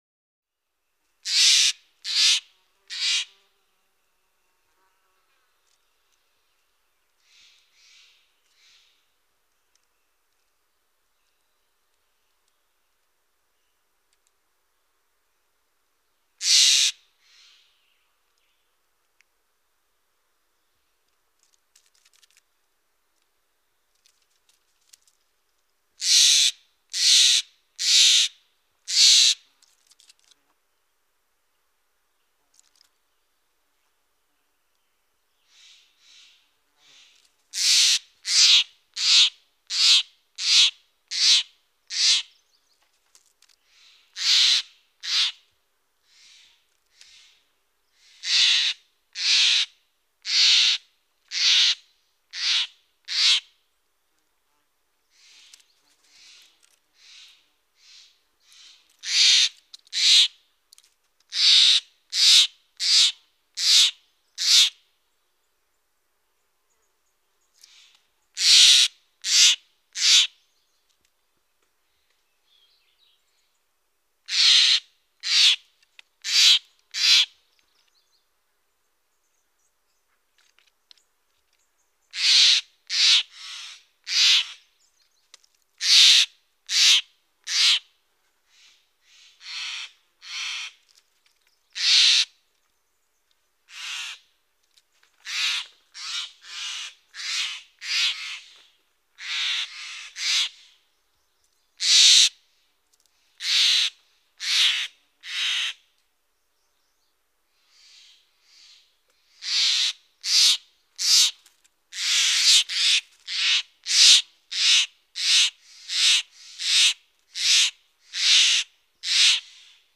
Jay, Stellar's Squawks. Interspersed Bird Squawks In The Foreground And Background, Along With Some Rustling And Leaf Noises. Medium Perspective.